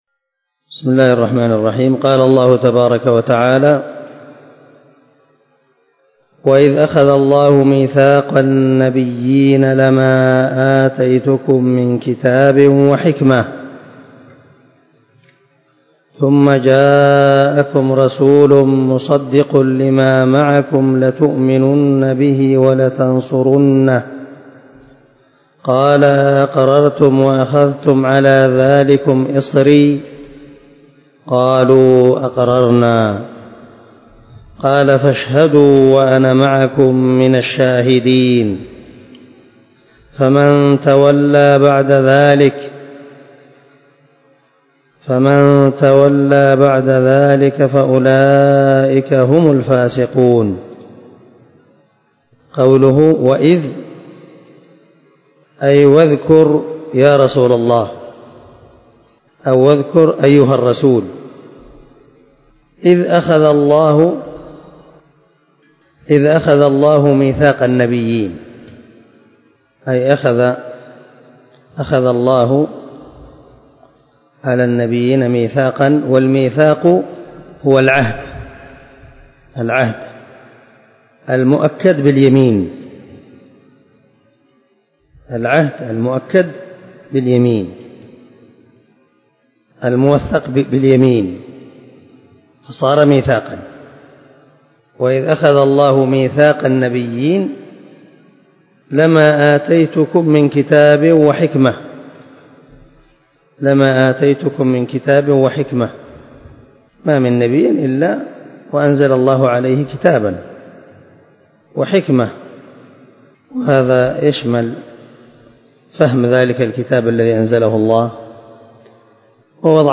180الدرس 25 تفسير آية ( 81 – 82 ) من سورة آل عمران من تفسير القران الكريم مع قراءة لتفسير السعدي